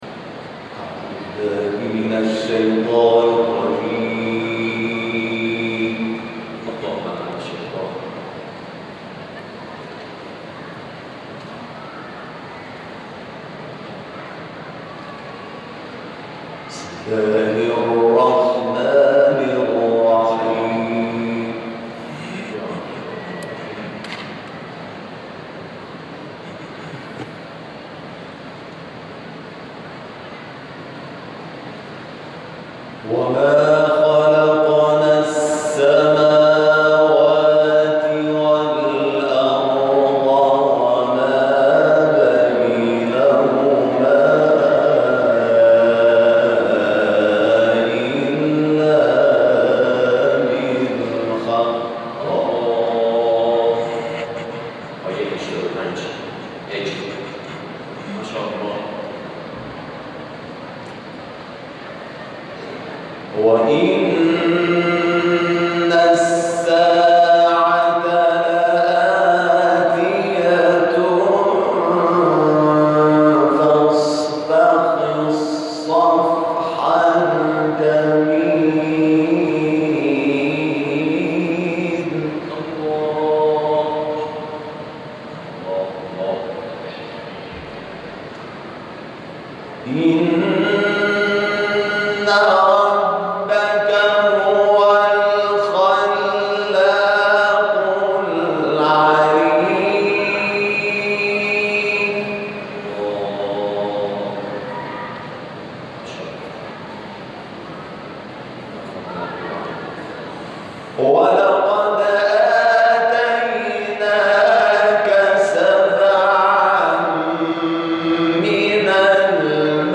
گروه شبکه‌های اجتماعی ــ تلاوت قاری بین‌المللی کشور را از سوره‌های مبارکه حجر و اعلی که در شهر مشهد مقدس اجرا شده است، می‌شنوید.